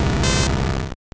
Cri de Magnéti dans Pokémon Or et Argent.